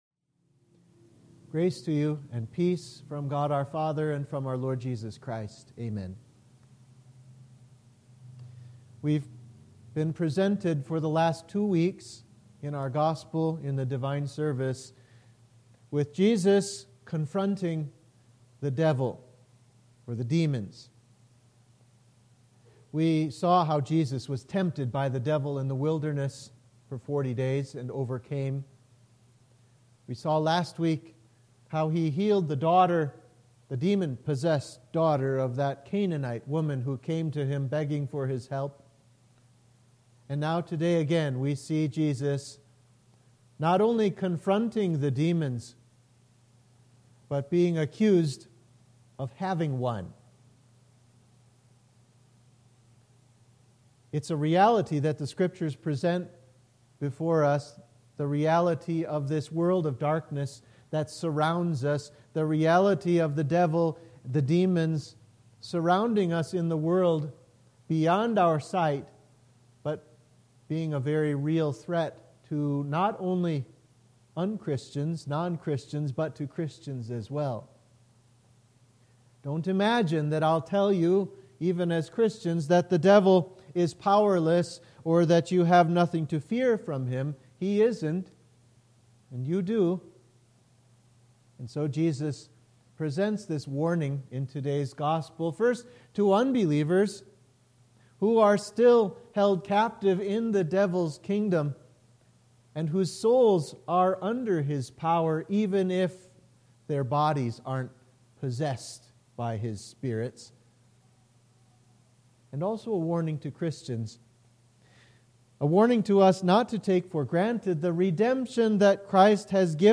Sermon for Oculi